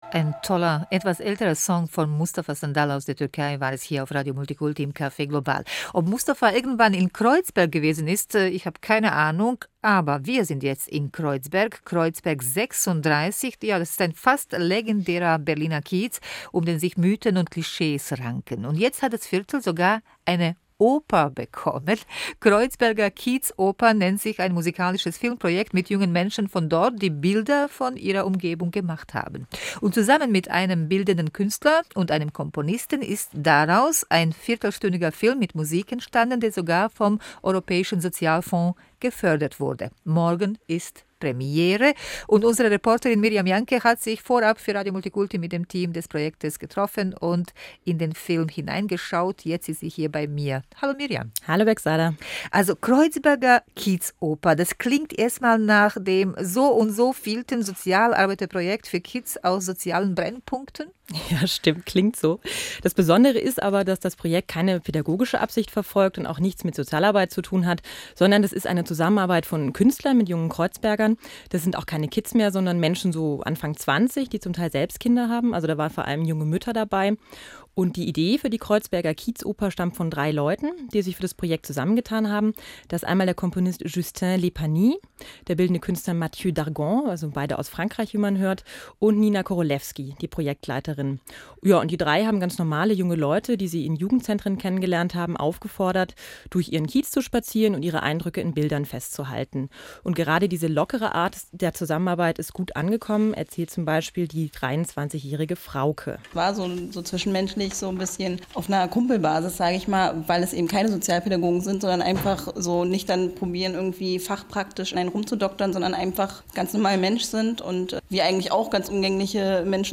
Kurzbeschreibung des Projektes [PDF] Einladung zur Kinopremiere [PDF] Kinoprogramm der Premiere [PDF] Interview Radiomultikulti vom RBB, 18.04.08 [MP3] Interview rfi radiofrance internationale, 16.05.08 [MP3]